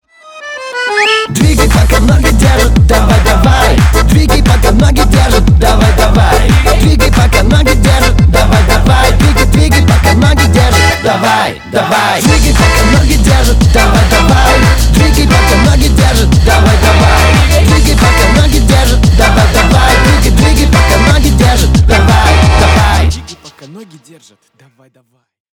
Танцевальные
ритмичные # весёлые